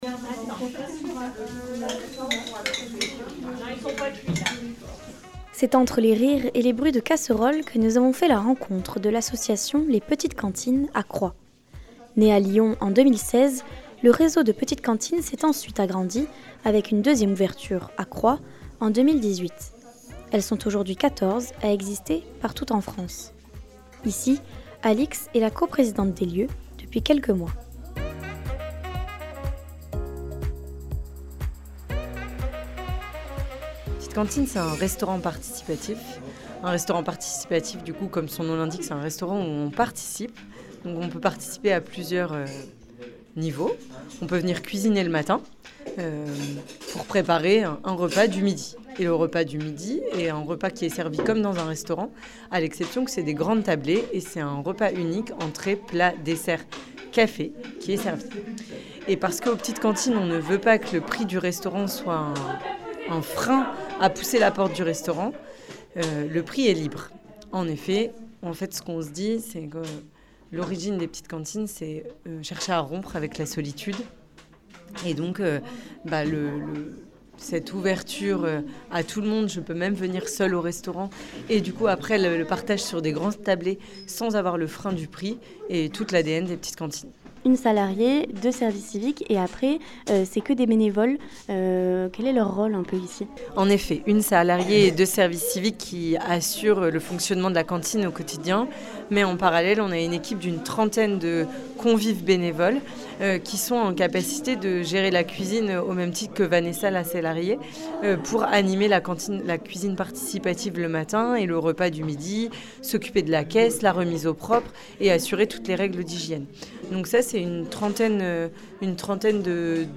Sur la base de la cuisine participative, l'association permet à ceux qui le souhaitent de venir passer un moment convivial autour de la cuisine. Installée à Croix depuis 2018, nous sommes entrés dans cet univers chaleureux et appétissant.
Un reportage